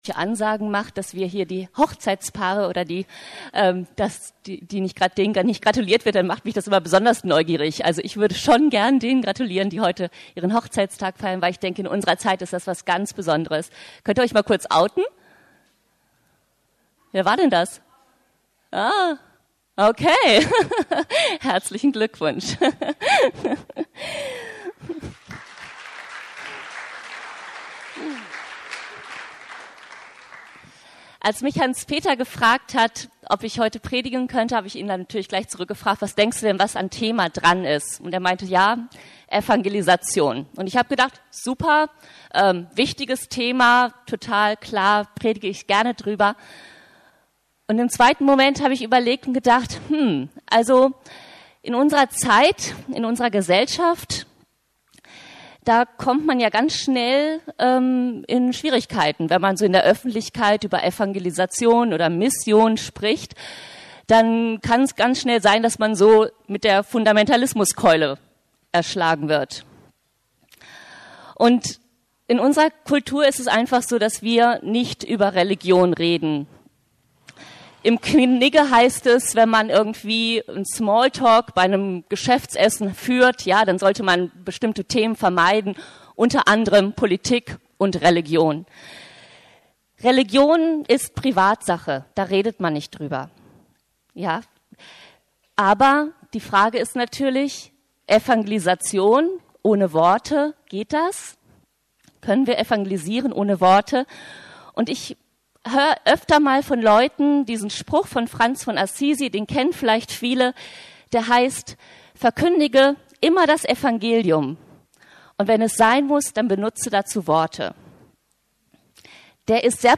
Vom Reden und Hören ~ Predigten der LUKAS GEMEINDE Podcast